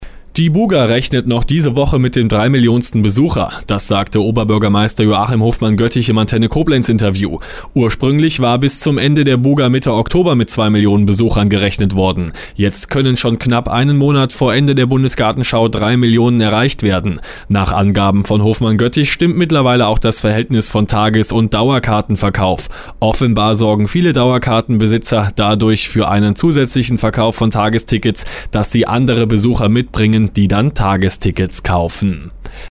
Antenne Koblenz 98,0 Nachrichten, 20.09.2011,  (Dauer 00:32 Minuten)